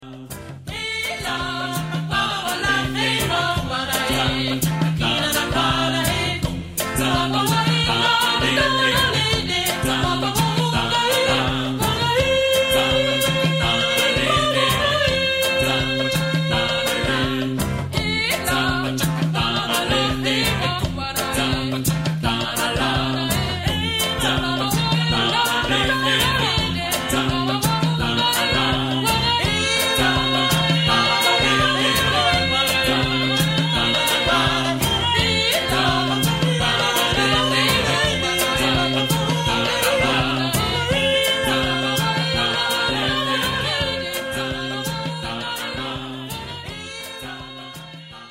Genre-Stil-Form: Kanon ; Ostinato ; weltlich
Chorgattung: FM  (2-stimmiger gemischter Chor )
Tonart(en): F-Dur